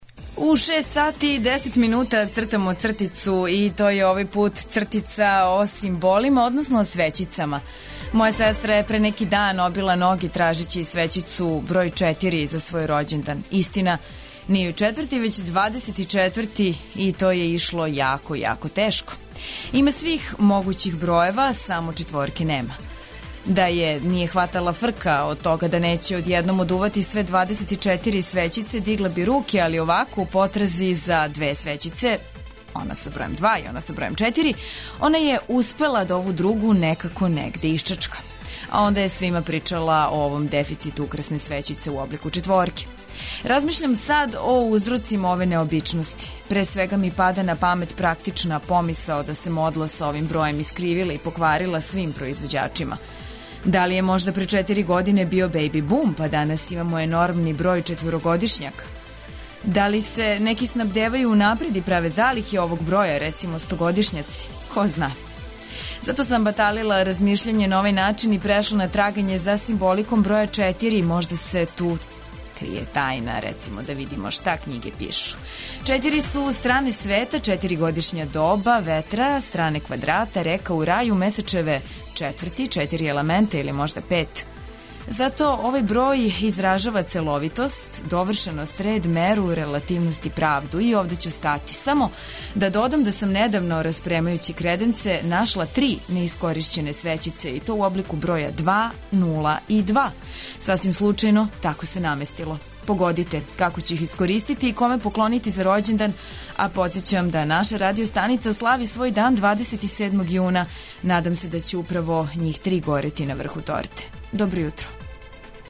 У инат захлађењу и са циљем призивања лепог времена, Устанак започињемо полетним поп-рок ритмовима.